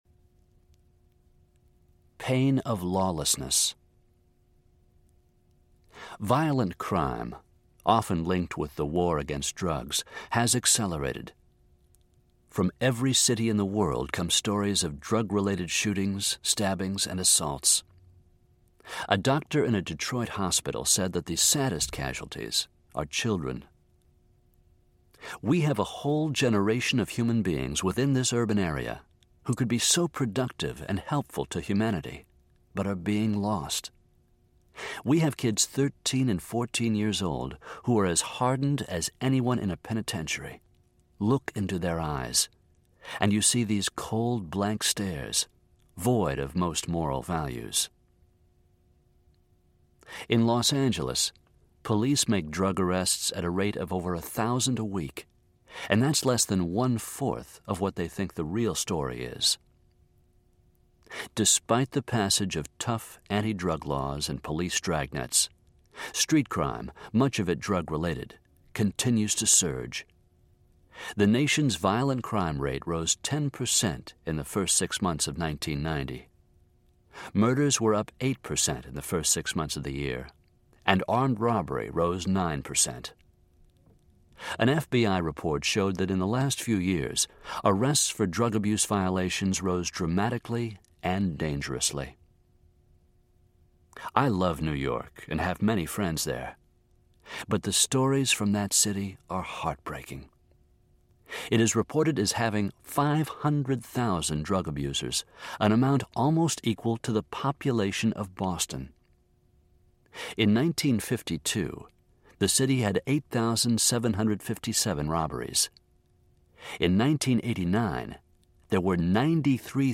Hope for the Troubled Heart Audiobook
Narrator